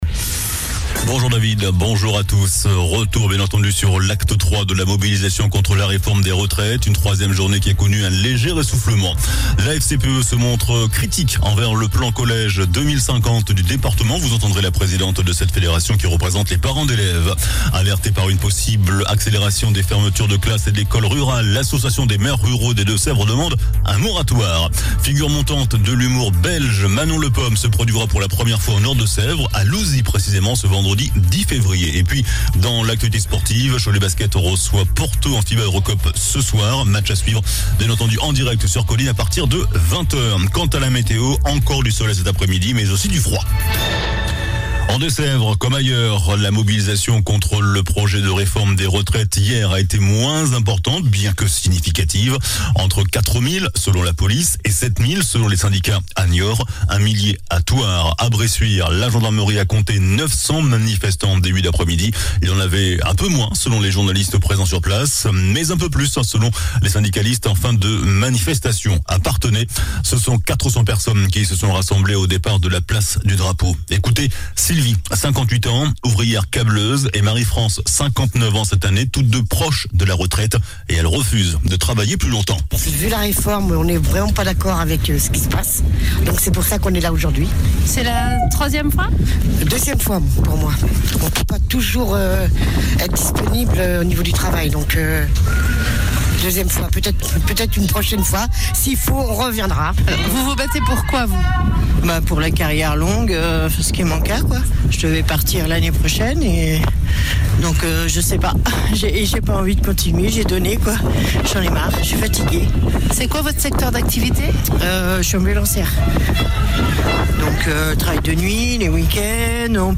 JOURNAL DU MERCREDI 08 FEVRIER ( MIDI )